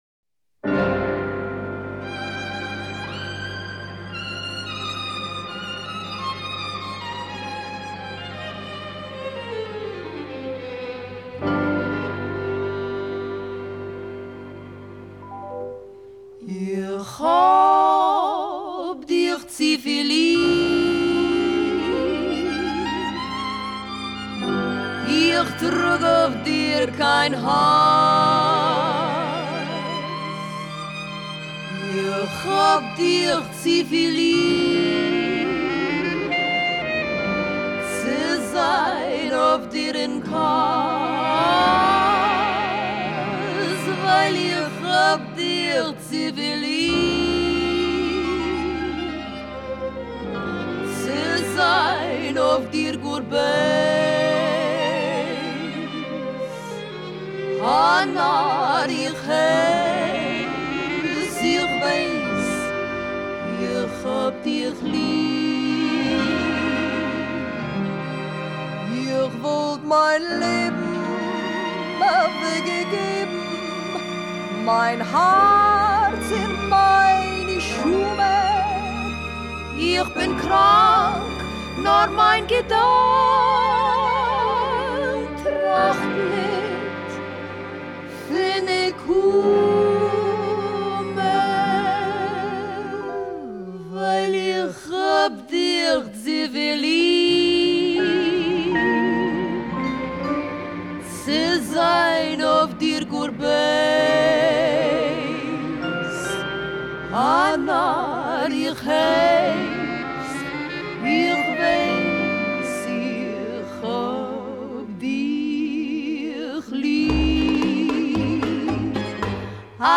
куда и вошло известное танго на идиш